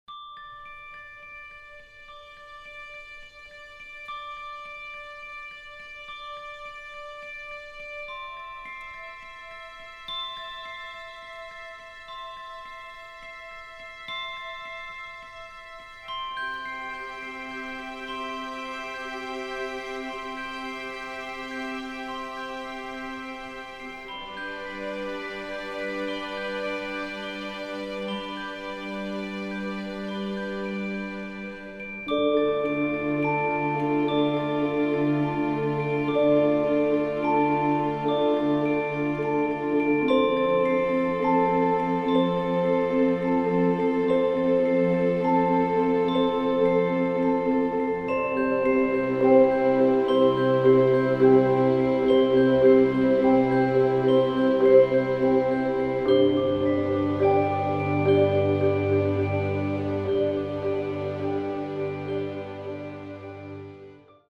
an emotionally evocative, thematically rich score